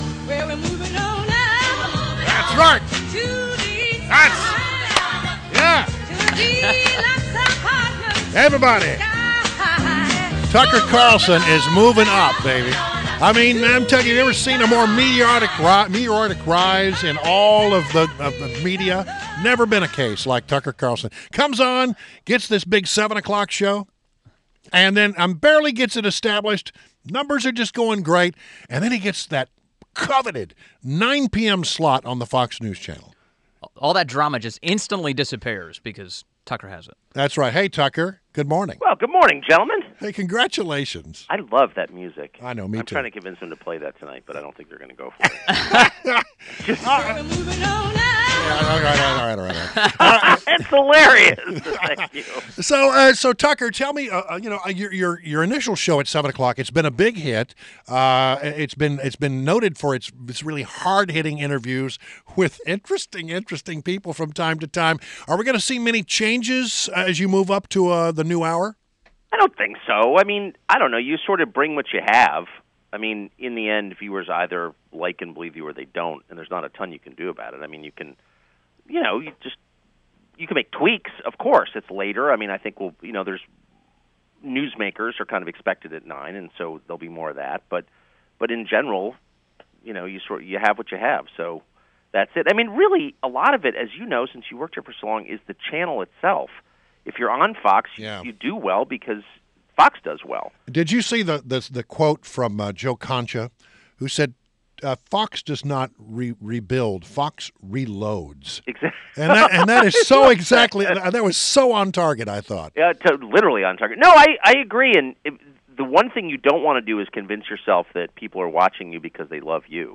WMAL Interview - TUCKER CARLSON - 01.09.17
INTERVIEW — TUCKER CARLSON – Host of the Fox News Channel show “Tucker Carlson Tonight” NOW AT 9 PM** and also the co-founder of The Daily Caller